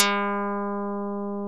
Index of /90_sSampleCDs/OMI - Universe of Sounds/EII Factory Library/85 Fretless Bass&Plucked Piano